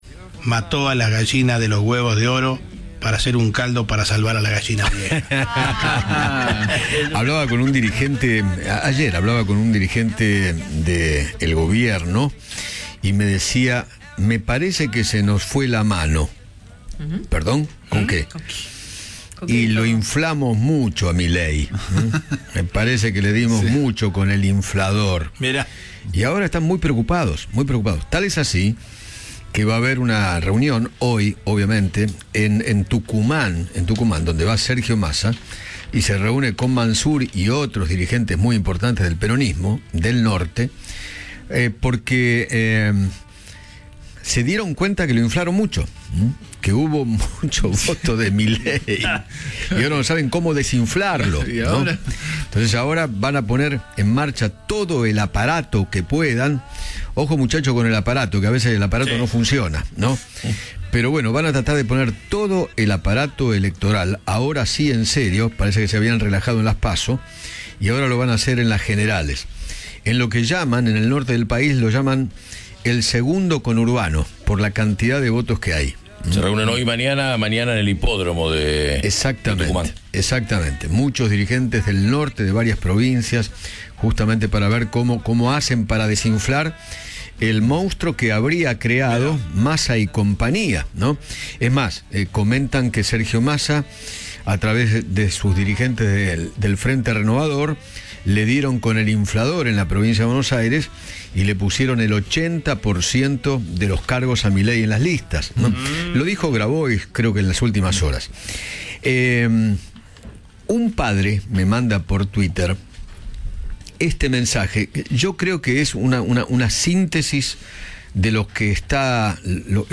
El conductor de Alguien Tiene que Decirlo sostuvo que el Gobierno está preocupado por Javier Milei.